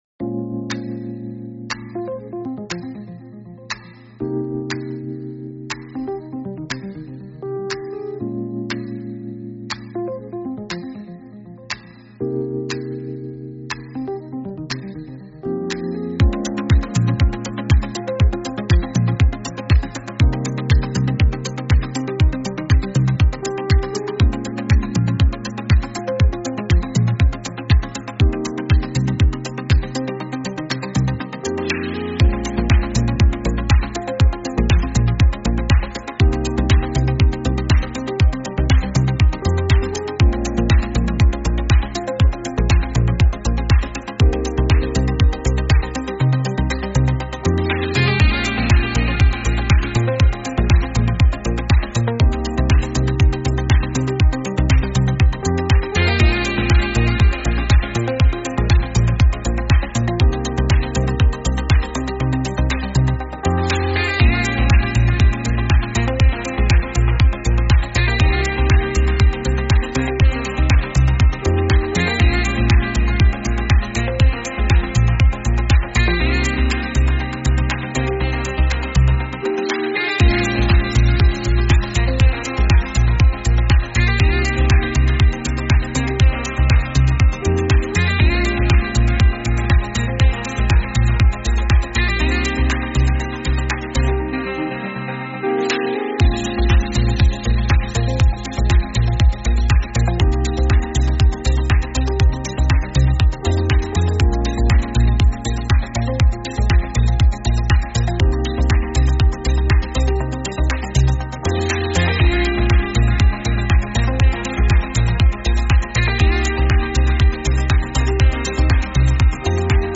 Instrumental -Real Liberty Media DOT xyz